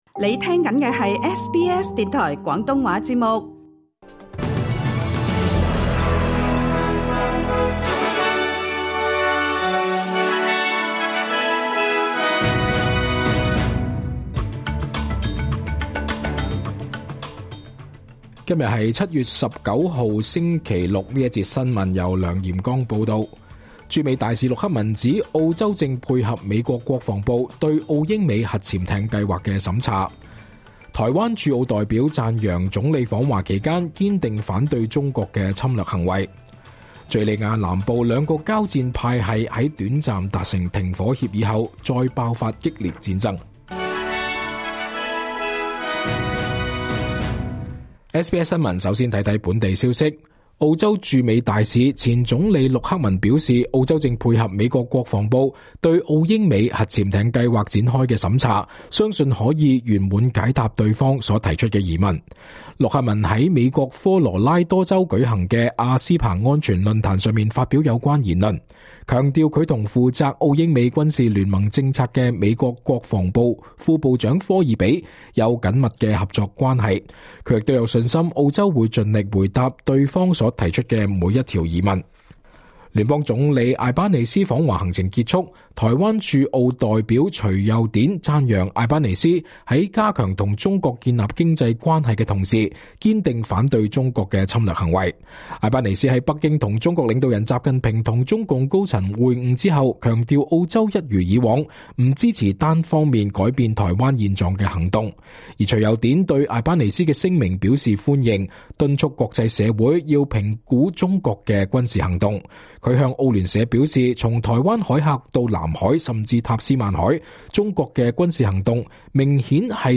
2025 年 7 月 19 日 SBS 廣東話節目詳盡早晨新聞報道。